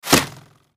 skyrim_bow_hitwall.mp3